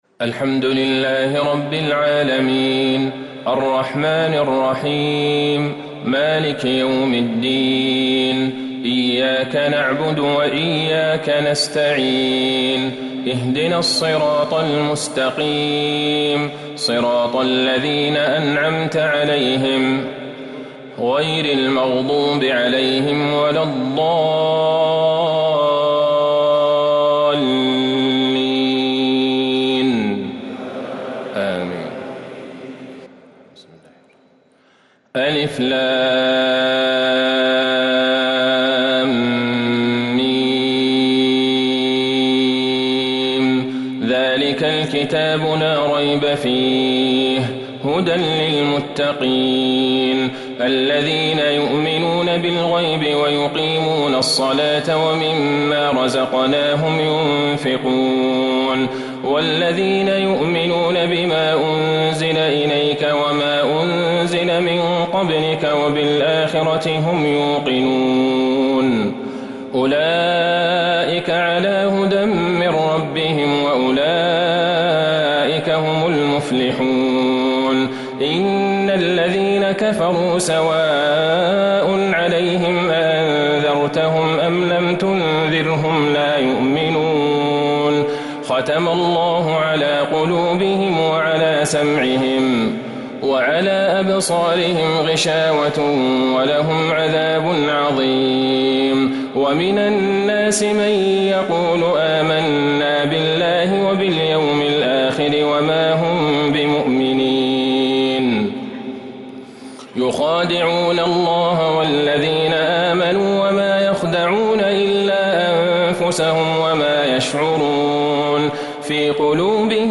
تراويح ليلة 1 رمضان 1444هـ من سورة البقرة ( 1-66 ) | Taraweeh 1st night Ramadan 1444H > تراويح الحرم النبوي عام 1444 🕌 > التراويح - تلاوات الحرمين